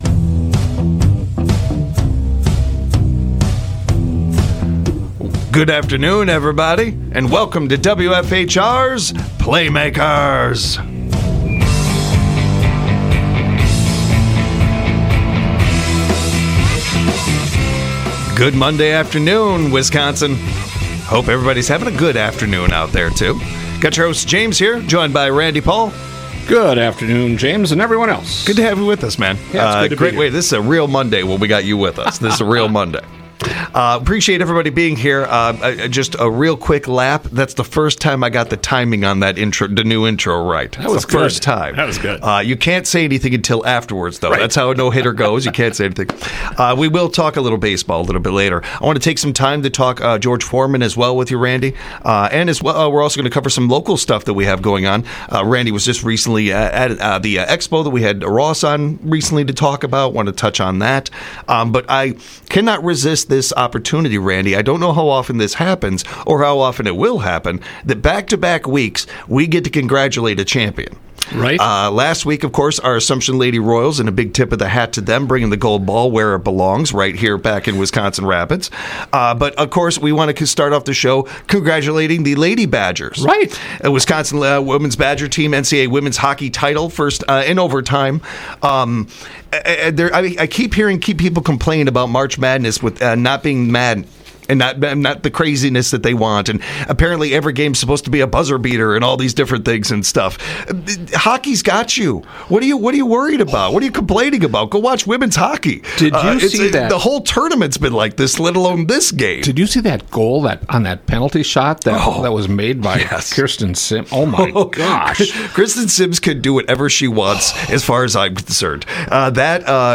This time the guys talk about the Wisconsin women's hockey team winning the national championship! They talked with listeners about the Badger Men's performance in the tournament. Then they discuss the legacy and life of George Foreman.